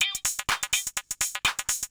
PERCLOOP064_HOUSE_125_X_SC2.wav